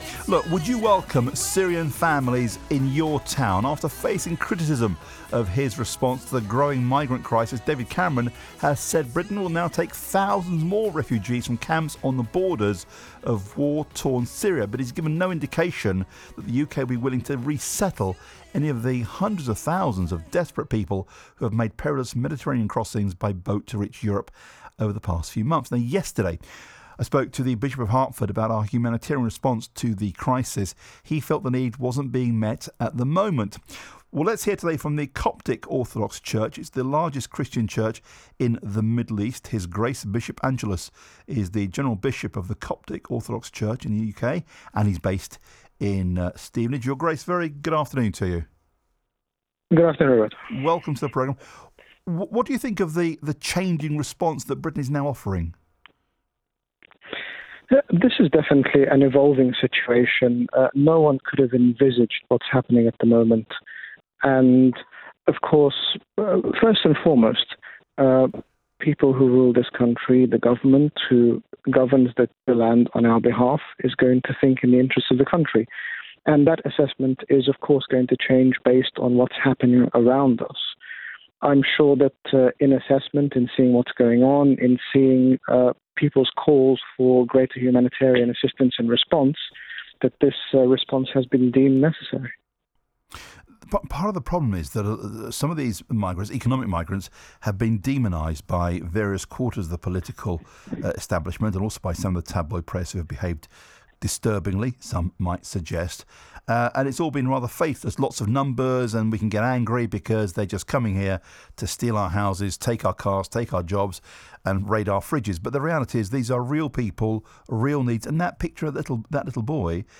In this interview, His Grace Bishop Angaelos, General Bishop of the Coptic Orthodox Church in the United Kingdom, speaks about the current refugee crisis affecting Europe.
BBC 3CR interview refugee crisis.mp3